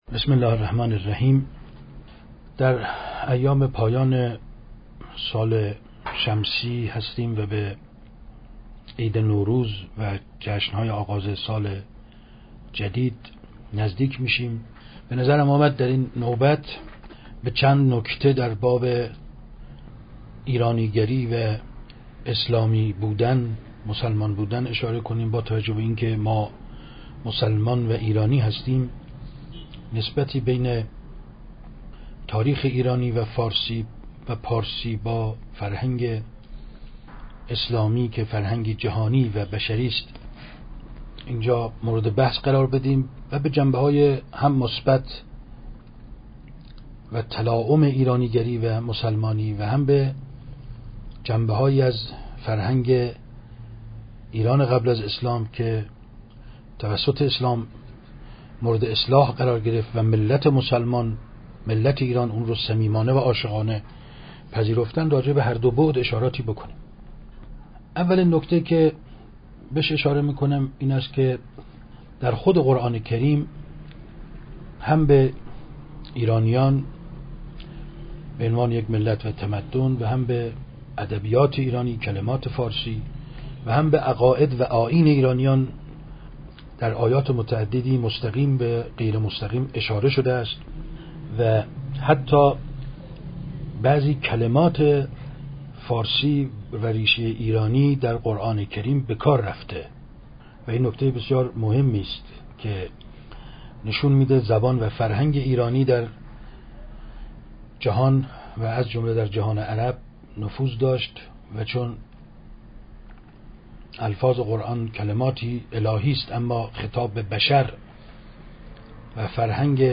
گفتاری از رحیم‌پور ازغدی پیرامون اسلام و ایران؛